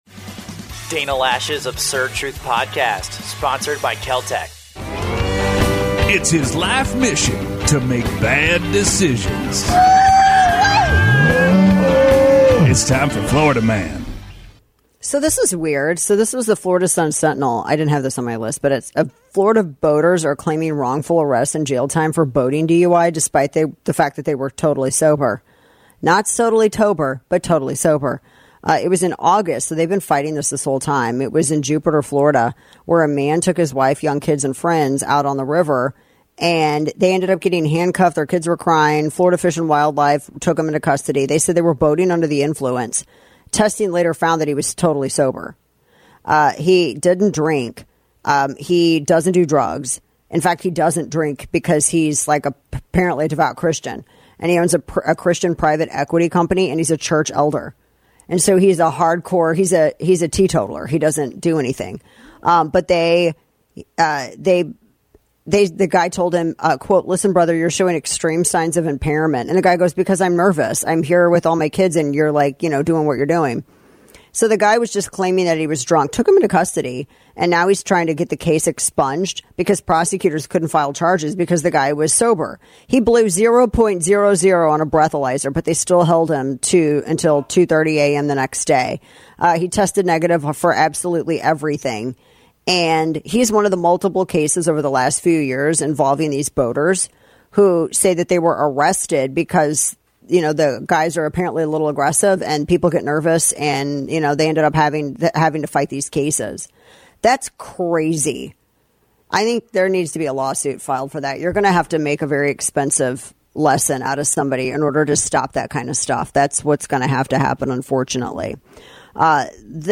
Dem Rep. Emanuel Cleaver tries his best to throw an insult at Elon Musk and ends up sounding like a confused stuttering cow.